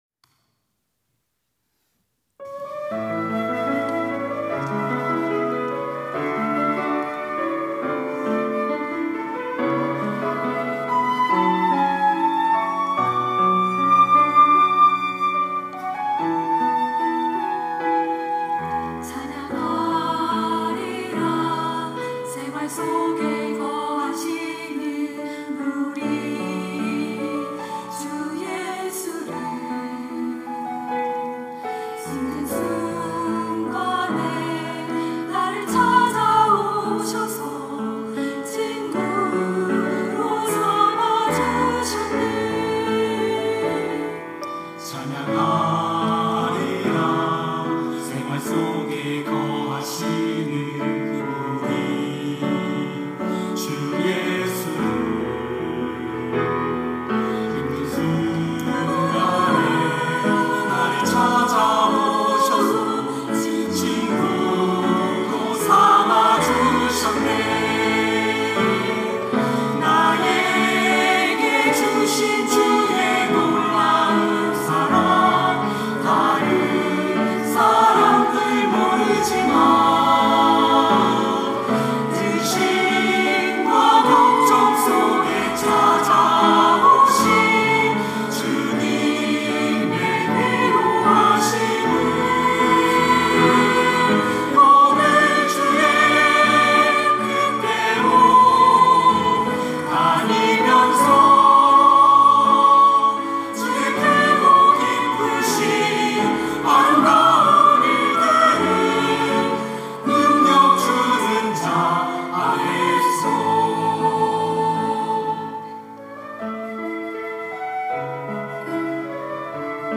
찬양대 부부